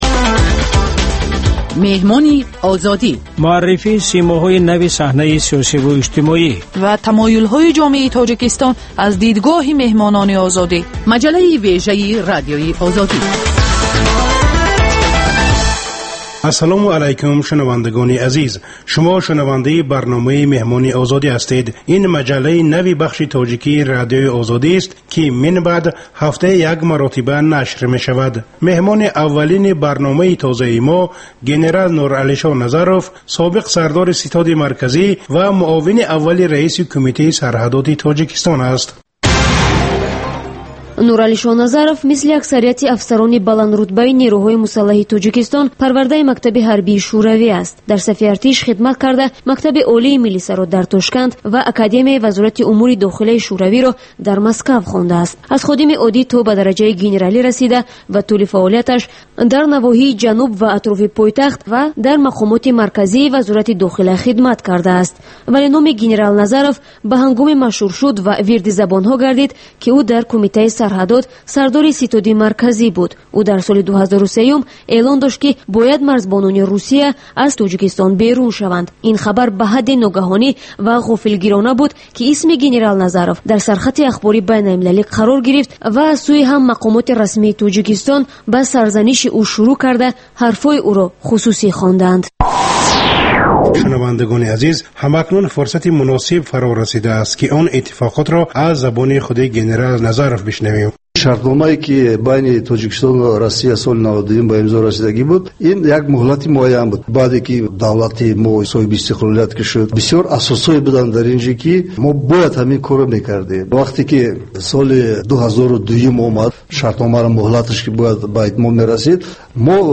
Гуфтугӯи ошкоро бо чеҳраҳои саршиноси Тоҷикистон.